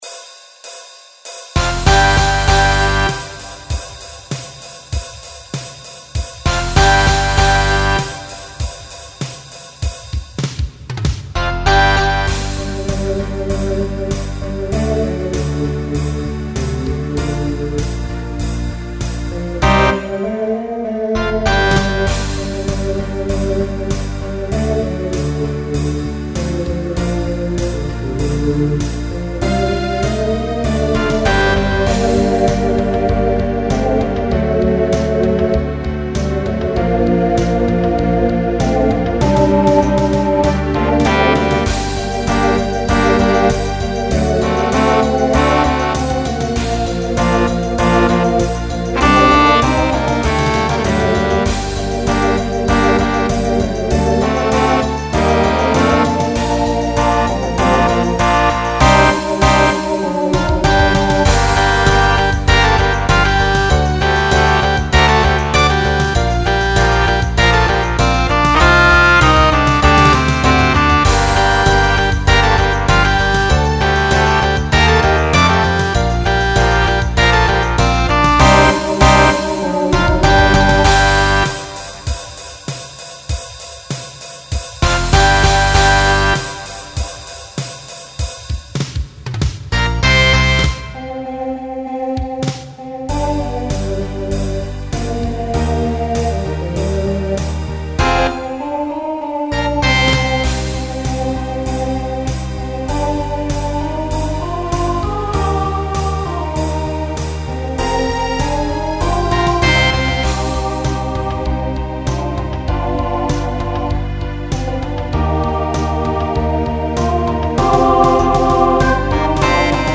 Genre Rock
Voicing Mixed